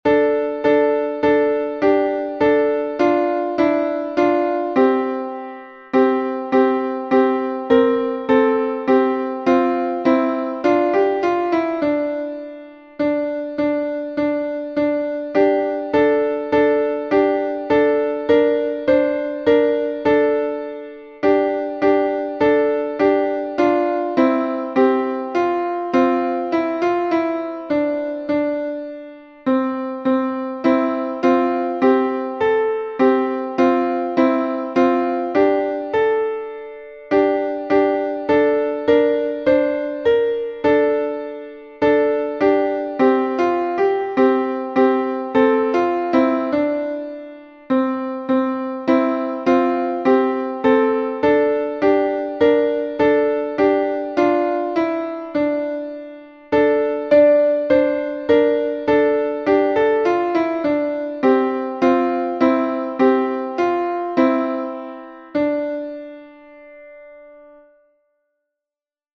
Византийский напев XV века